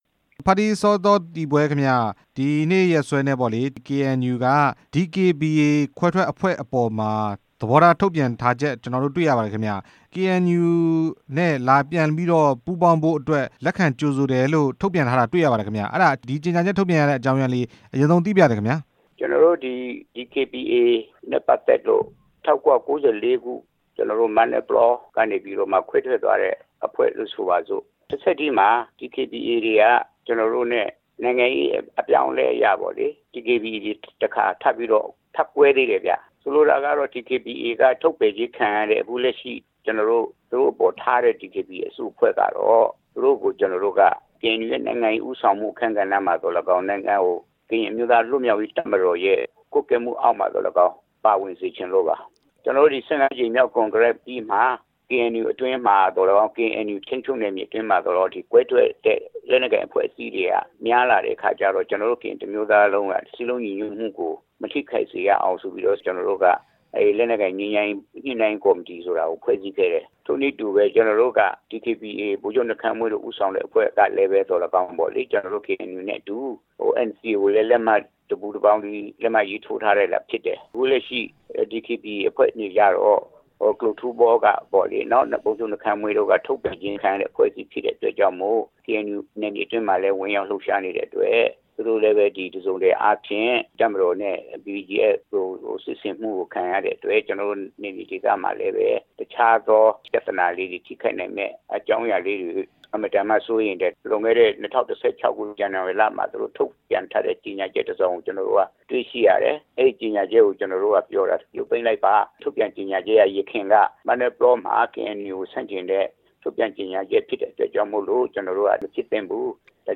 DKBA ခွဲထွက်ကို KNUနဲ့ ပူးပေါင်းဖို့ ကြေညာချက်ထုတ်တဲ့အပေါ် မေးမြန်းချက်